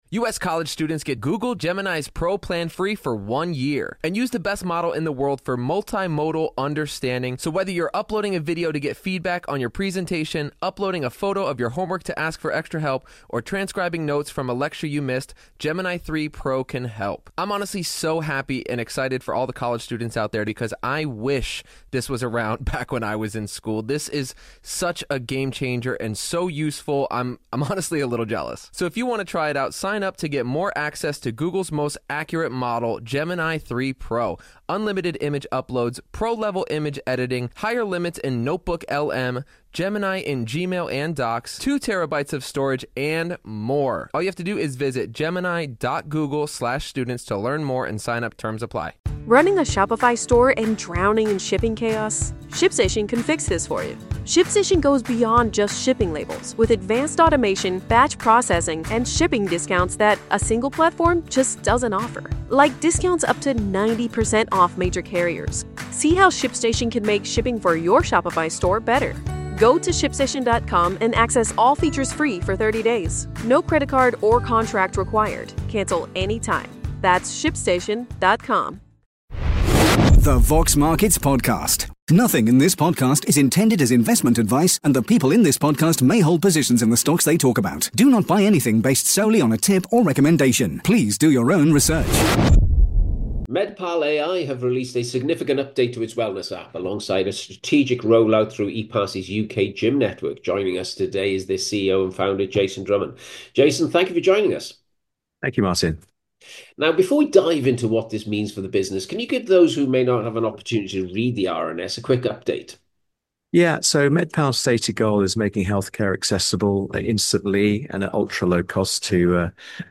In this exclusive interview